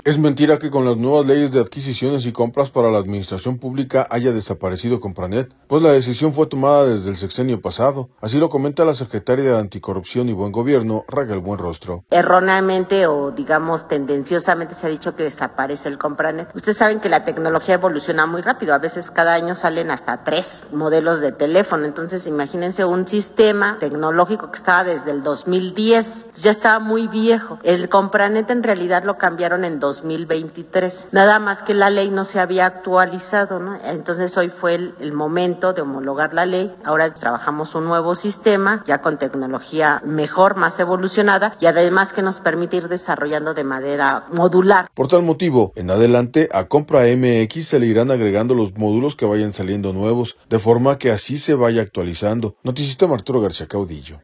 Es mentira que con las nuevas leyes de adquisiciones y compras para la administración pública haya desaparecido Compranet, pues la decisión fue tomada desde el sexenio pasado, así lo comenta la secretaria de Anticorrupción y Buen Gobierno, Raquel Buenrostro.